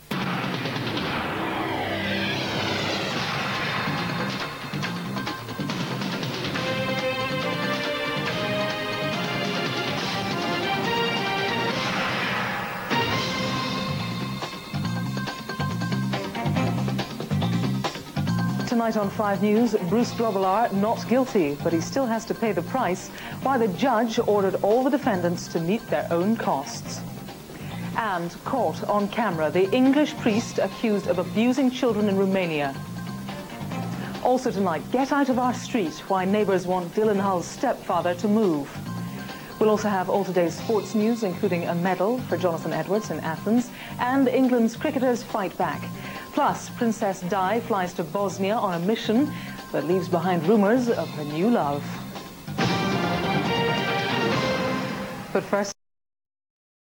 The original 5 news, presented from the newsroom at ITN.
The opening titles and headlines to the main five news programme on Friday August 8th 1997 at 8.30pm.